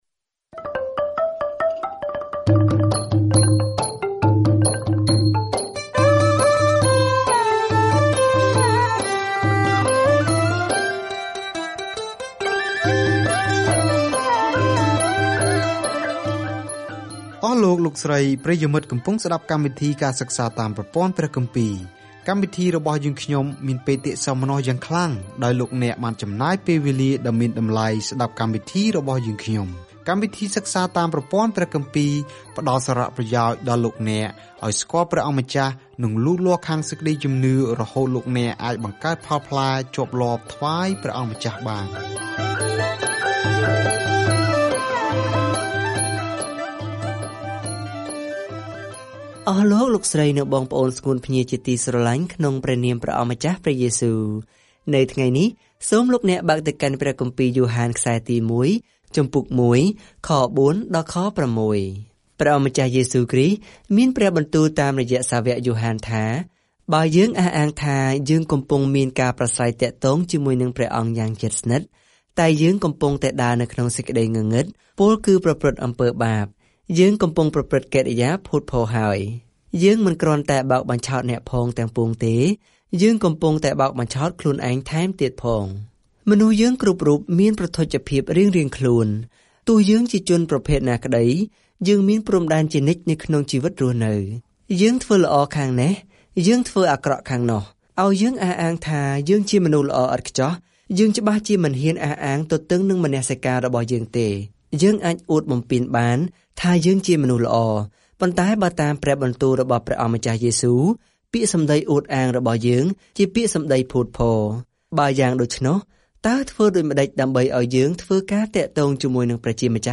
ការធ្វើដំណើរប្រចាំថ្ងៃតាមរយៈ យ៉ូហានទី 1 នៅពេលអ្នកស្តាប់ការសិក្សាជាសំឡេង ហើយអានខគម្ពីរដែលជ្រើសរើសចេញពីព្រះបន្ទូលរបស់ព្រះ។